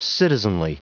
Prononciation du mot citizenly en anglais (fichier audio)
Prononciation du mot : citizenly